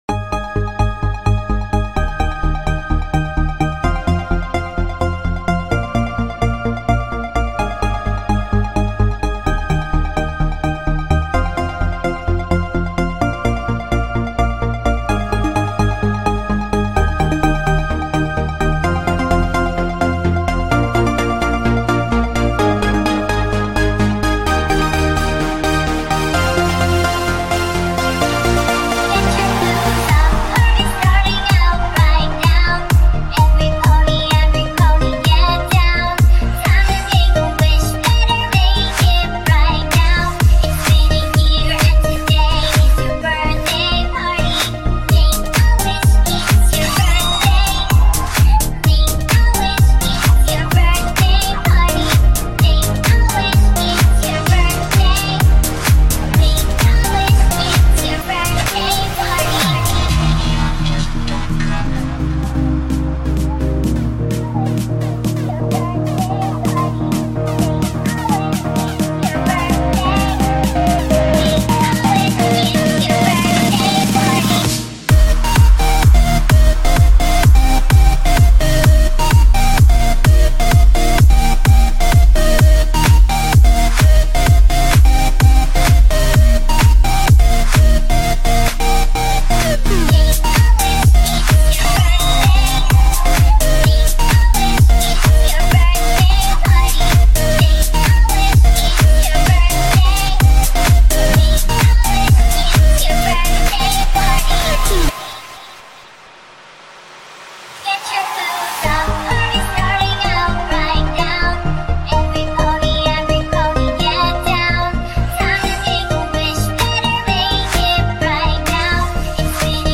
(Please be aware that the free download will be watermarked.